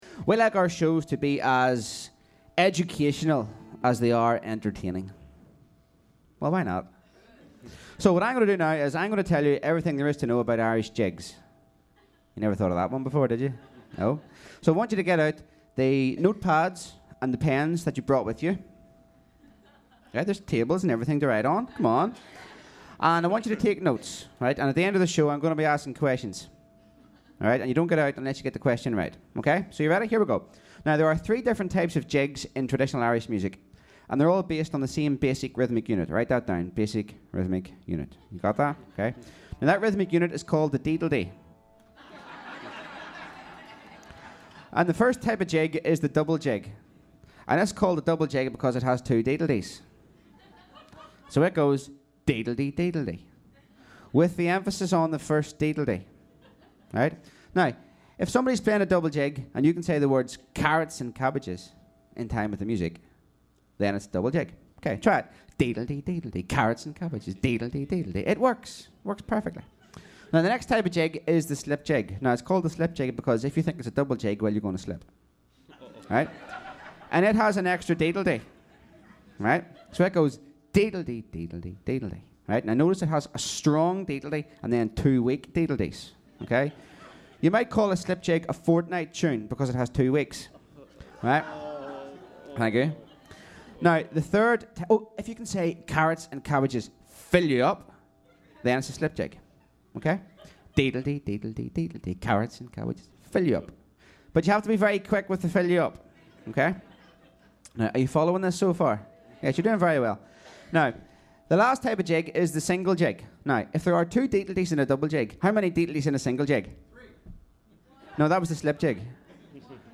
comes from that concert.